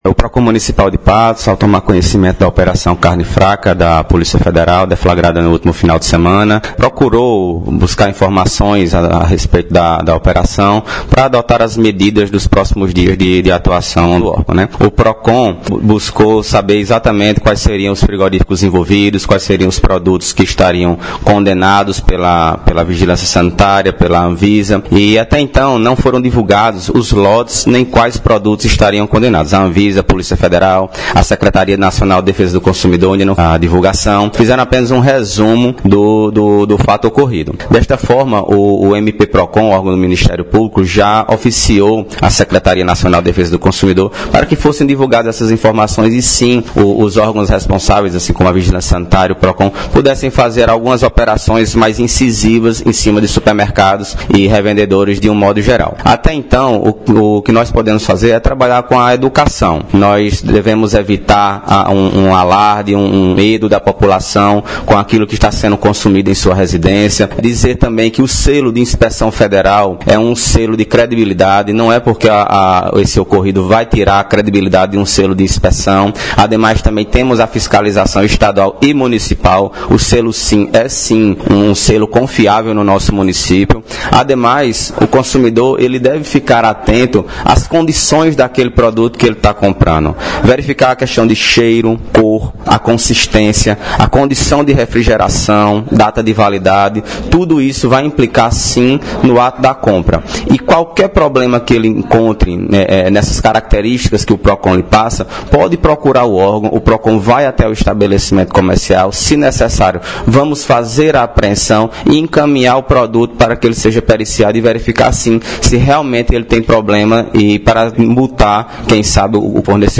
Secretário do PROCON-Patos, Bruno Maia –